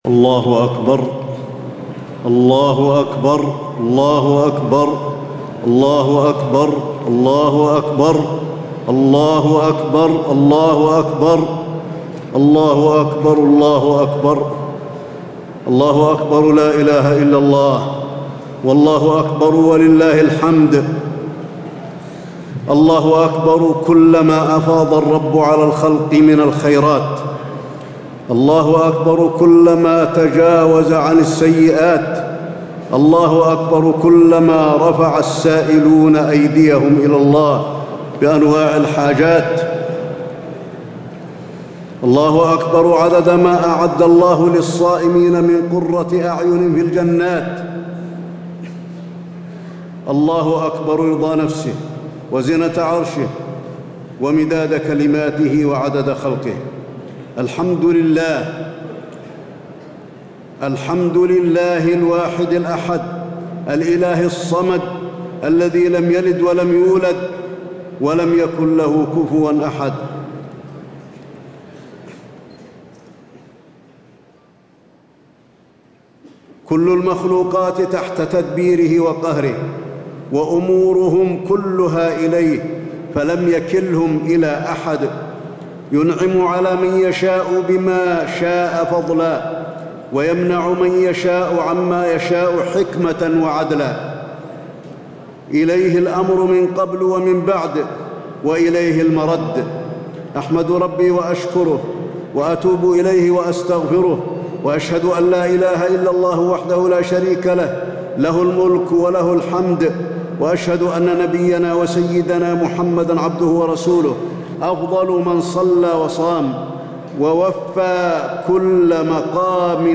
خطبة عيد الفطر - المدينة - الشيخ علي الحذيفي - الموقع الرسمي لرئاسة الشؤون الدينية بالمسجد النبوي والمسجد الحرام
تاريخ النشر ١ شوال ١٤٣٦ هـ المكان: المسجد النبوي الشيخ: فضيلة الشيخ د. علي بن عبدالرحمن الحذيفي فضيلة الشيخ د. علي بن عبدالرحمن الحذيفي خطبة عيد الفطر - المدينة - الشيخ علي الحذيفي